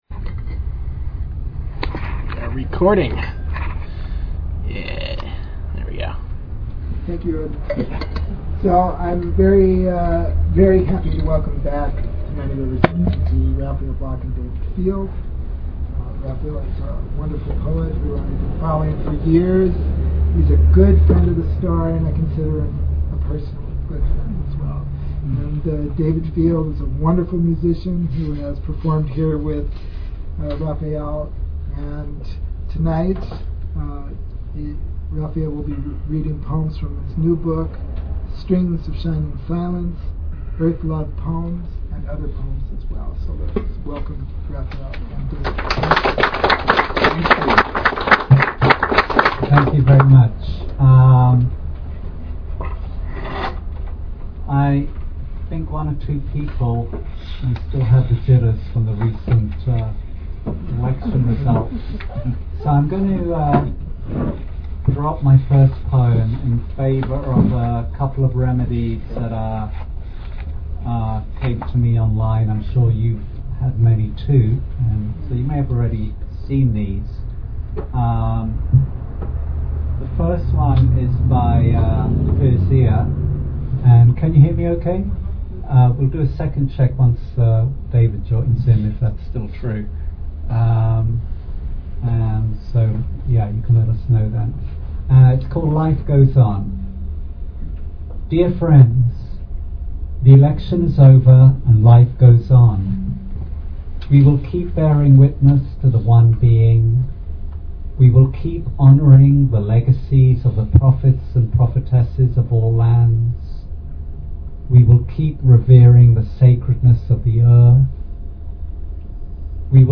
Archive of an event at Sonoma County's largest spiritual bookstore and premium loose leaf tea shop.
performance of poetry and acoustic guitar
unique, romantic and flowing style
sensitive, contemplative arrangements